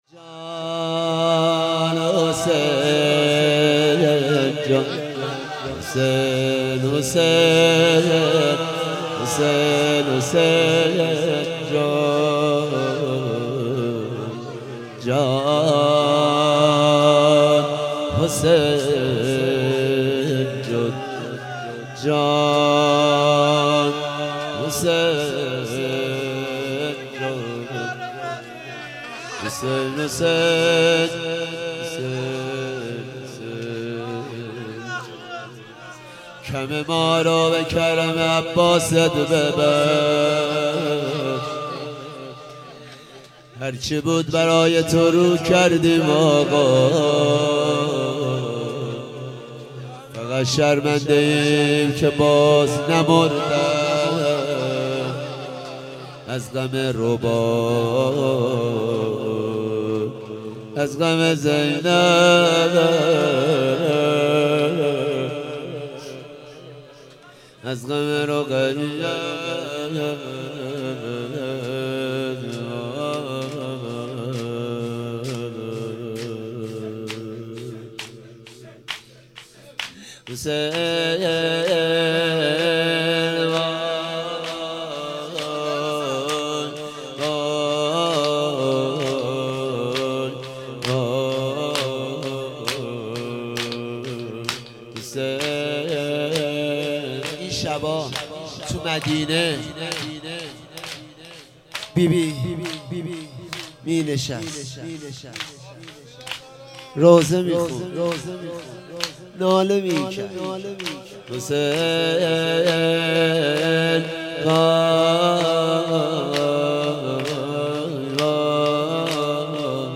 دهه آخر صفر - شب دوم - روضه - جان حسین حسین حسین